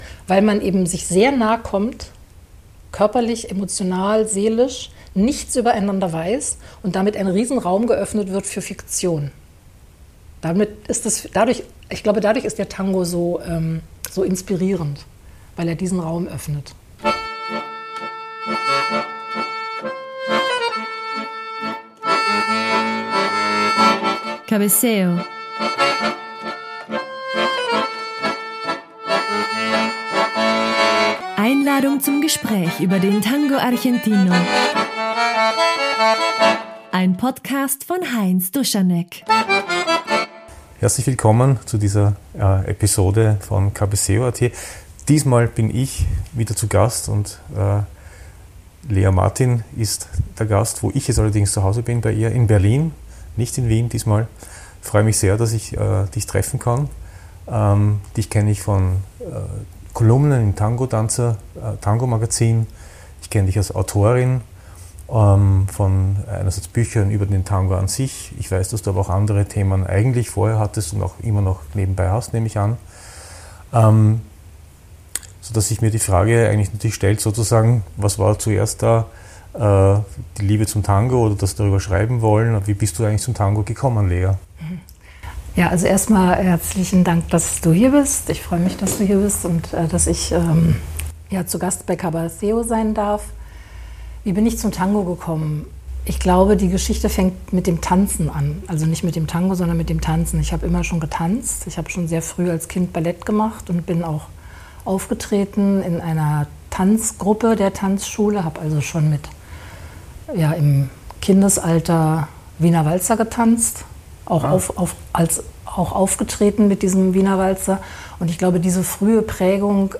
In unserem Gespräch streifen wir auch dieses Thema kurz an.